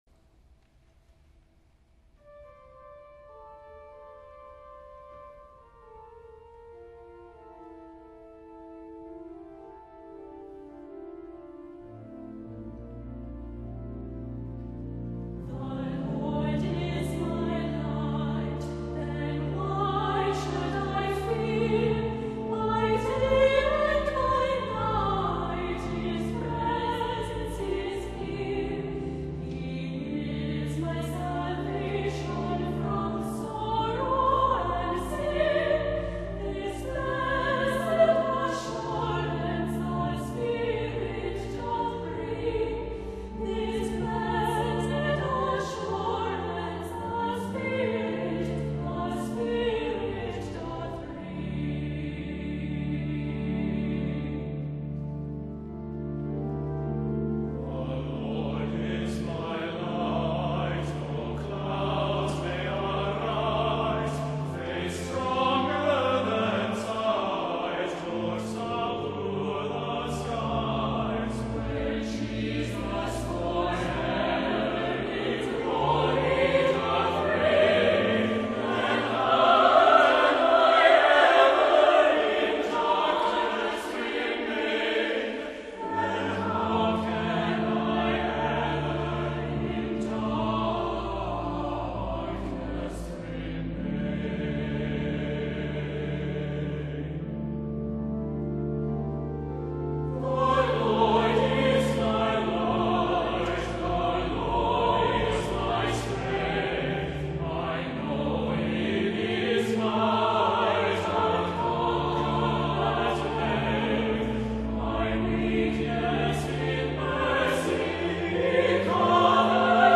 * a lovely, hymn-style praise anthem